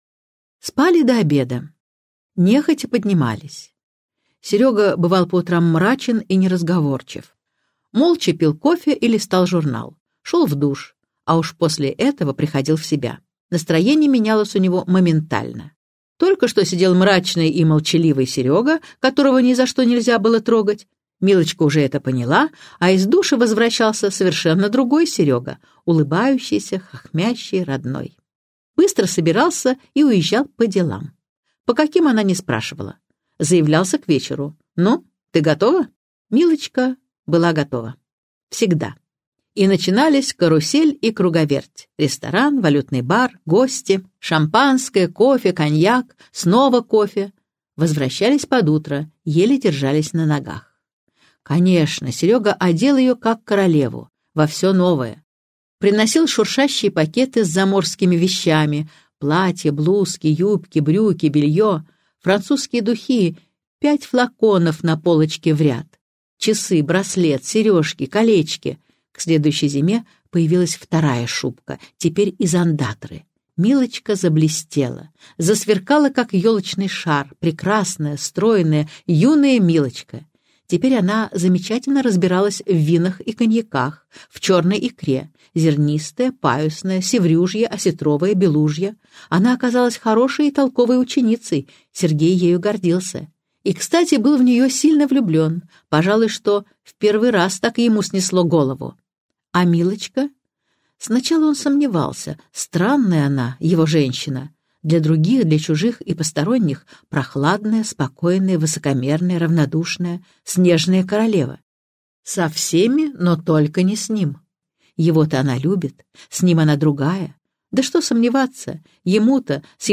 Аудиокнига Я буду любить тебя вечно | Библиотека аудиокниг